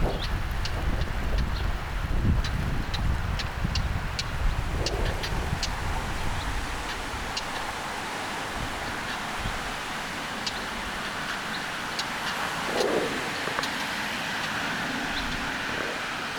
kivitaskun poikasen ääntelyä?, 1
muistaakseni_kivitaskun_poikasen_aantelya.mp3